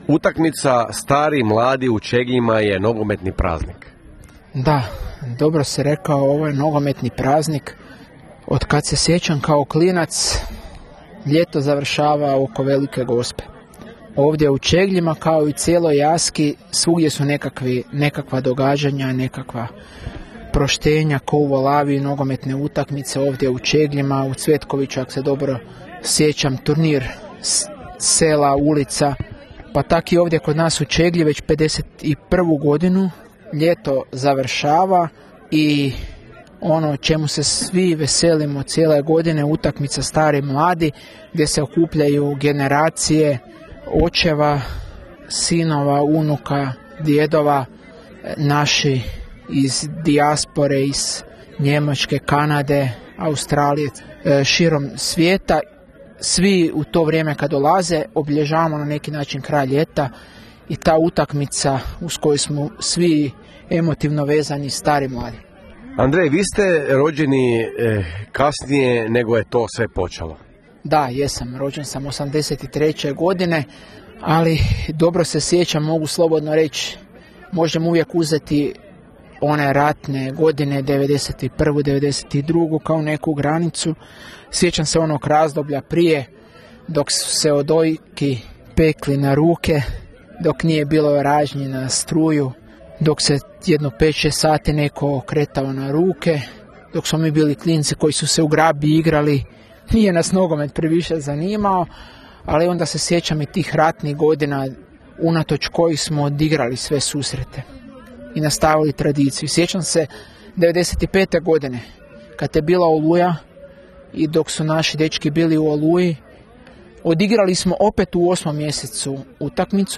Bili smo u Čegljima na susretu starih i mladih